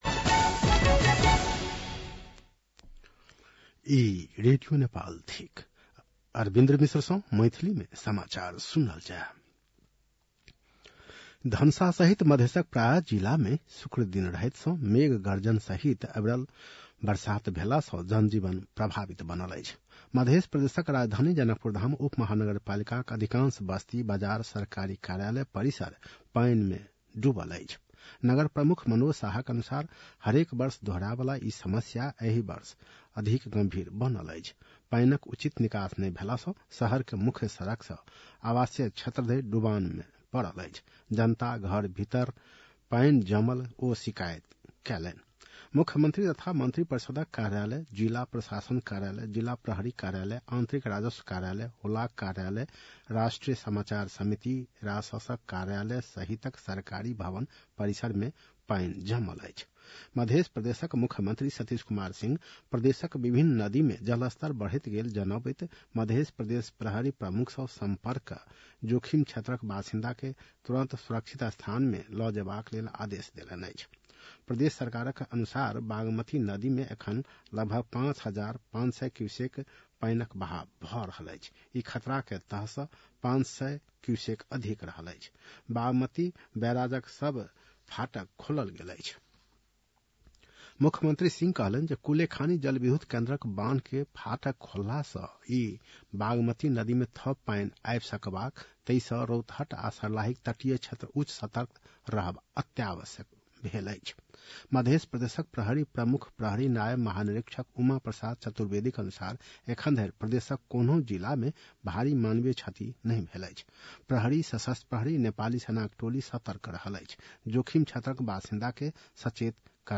मैथिली भाषामा समाचार : १९ असोज , २०८२
Maithali-news-6-19.mp3